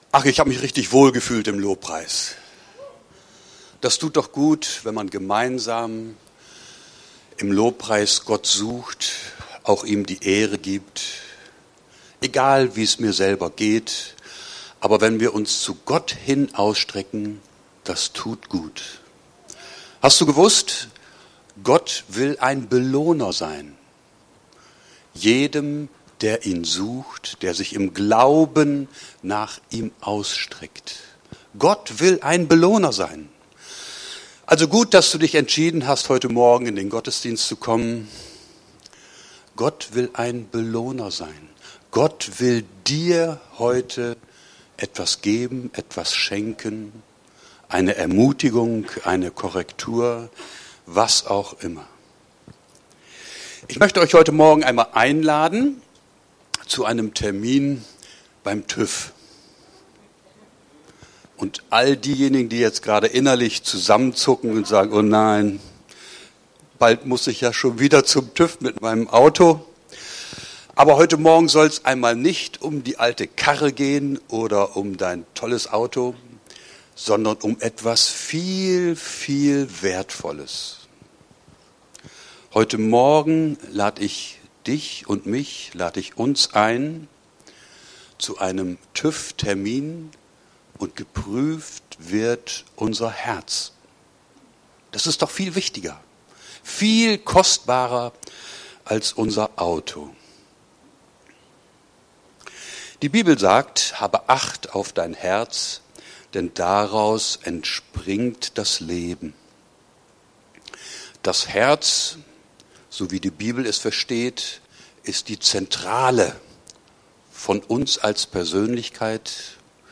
Aktuelle Predigten aus unseren Gottesdiensten und Veranstaltungen